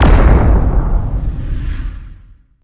Explosion and footstep SFX
Explosion.wav